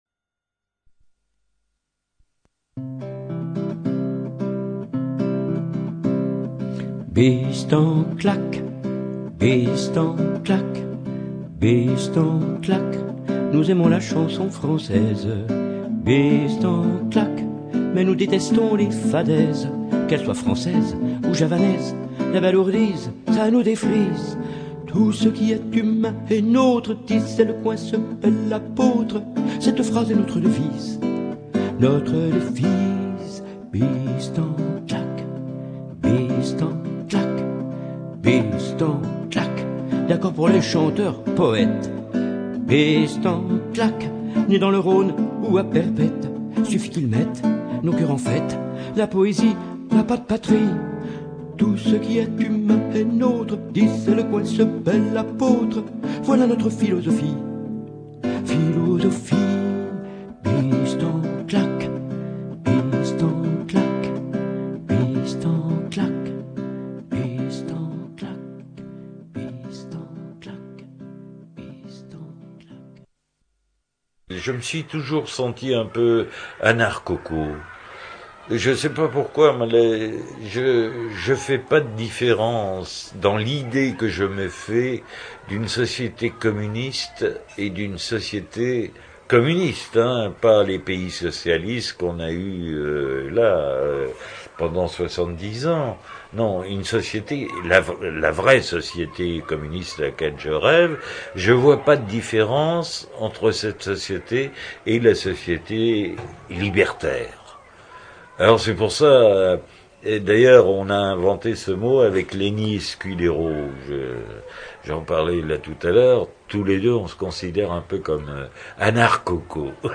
ceci est la 4 ème émission réalisée à partir de l’entretien que Claude VINCI à bien voulu accorder à l’émission en août 2003